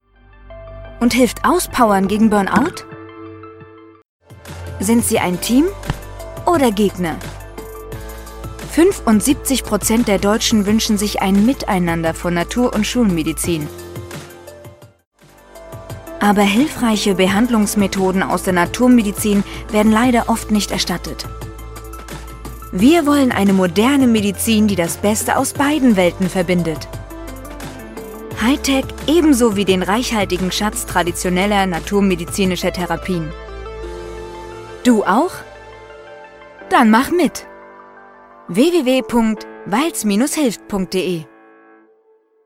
Mezzosopran, Kinder- und Jugendstimme möglich, Stimmalter bis 35 Jahre, junge, warme und angenehme Stimmfarbe erfrischend und dynamisch, sinnlich, seriös, authentisch
Sprechprobe: eLearning (Muttersprache):